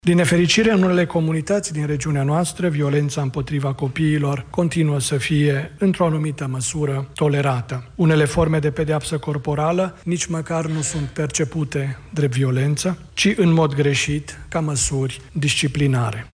Situația a fost analizată într-o conferință găzduită de ministerul Muncii și Familiei, la care au participat și reprezentanții UNICEF și ONU.
Consilierul prezidențial Marius Lazurca: „Una din șase adolescente cu vârsta cuprinsă între 15 și 19 ani a fost victima violenței fizice sau sexuale din partea soțului sau partenerului”